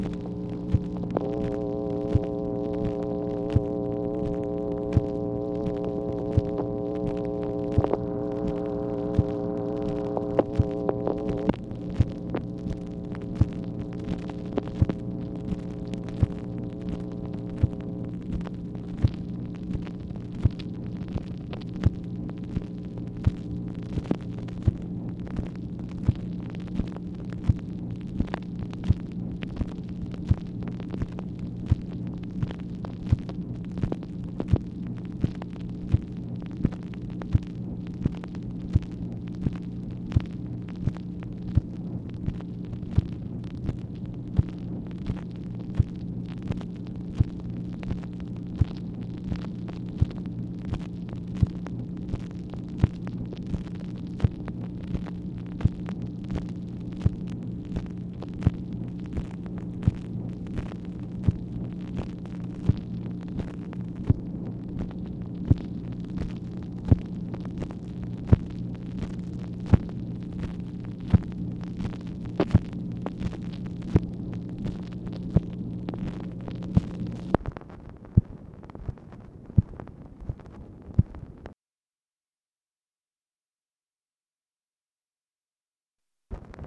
MACHINE NOISE, May 4, 1965
Secret White House Tapes | Lyndon B. Johnson Presidency